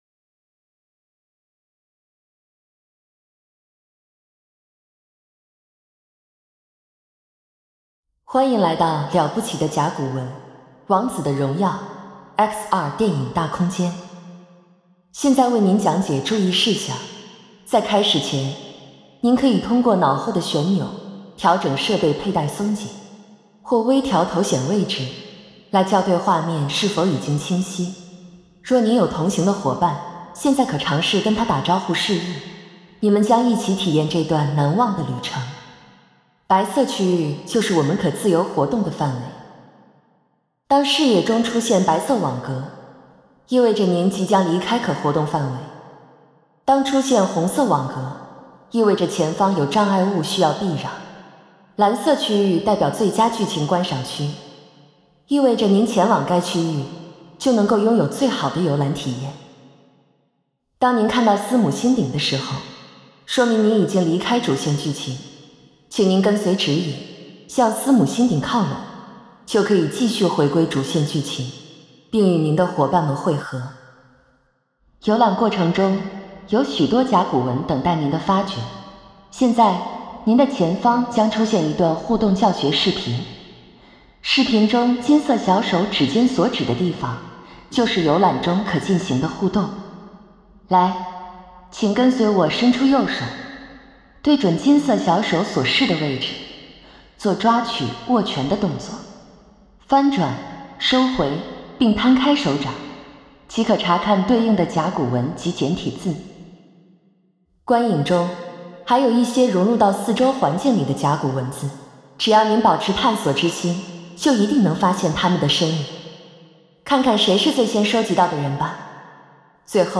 ADR_Intro_VoiceGuide_02.ogg